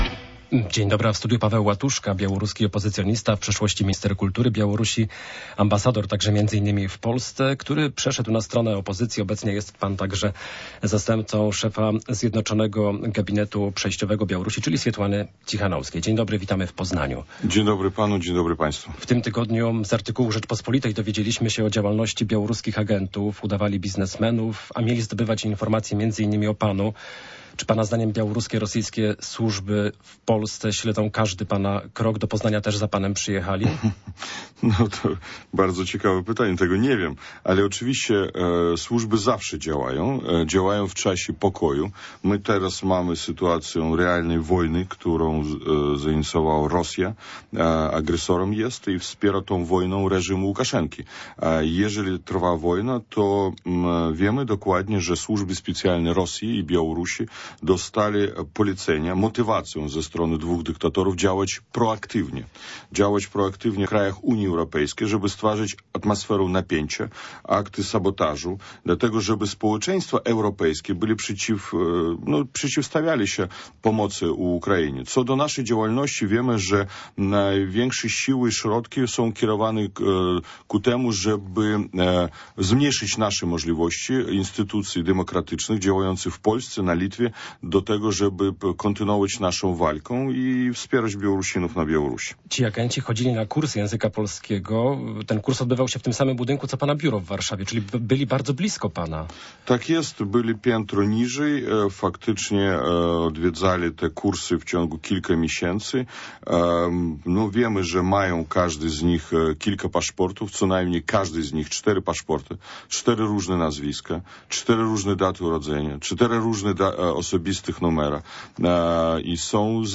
Gościem Radia Poznań jest jeden z liderów białoruskiej opozycji Paweł Łatuszka, który przyjechał do stolicy Wielkopolski na Poznański Kongres Gospodarczy.